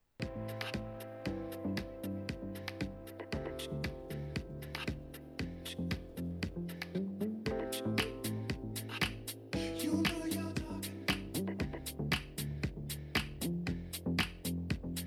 Как то не занимался раньше записью аудио с динамиков через микрофон )) Микрофон ecm8000 и карта emu0404.